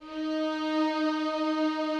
strings_051.wav